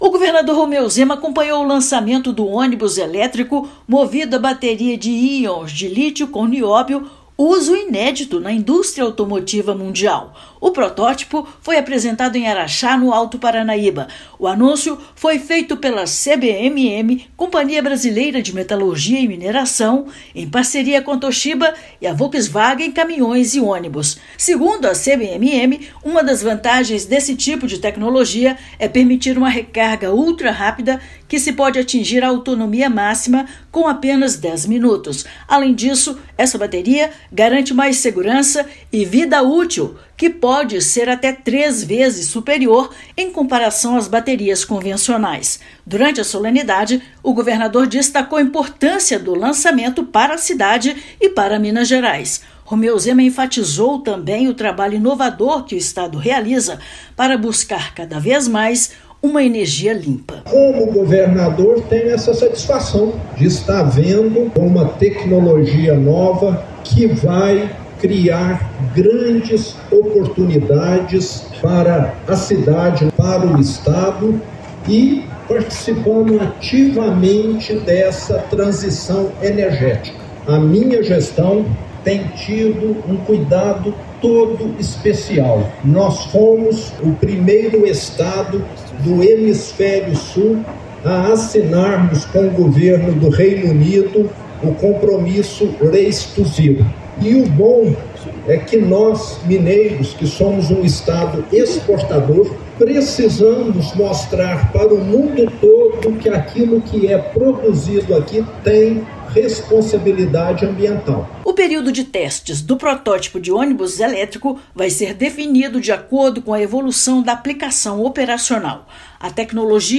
Agência Minas Gerais | [RÁDIO] Governo de Minas acompanha lançamento do primeiro ônibus elétrico no mundo com bateria de nióbio, em Araxá
Protótipo dispõe de recarga ultrarrápida e reforça atuação do Estado na transição energética. Ouça a matéria de rádio: